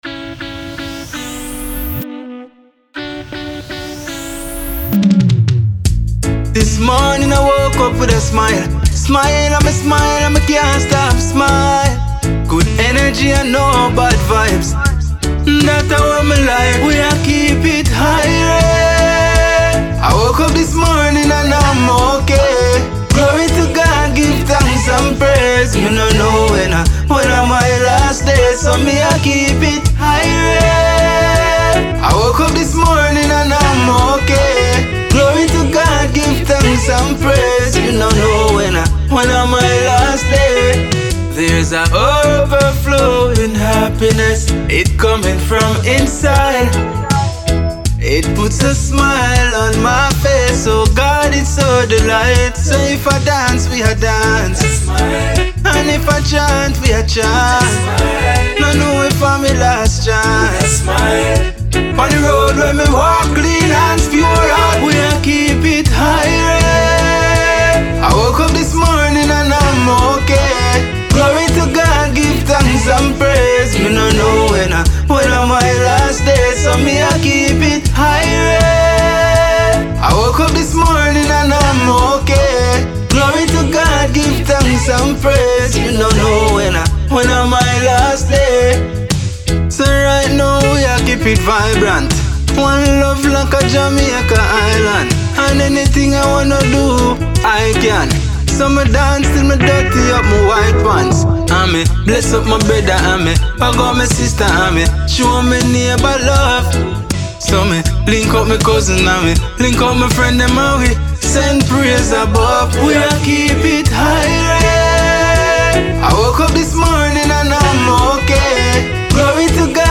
Jamaican /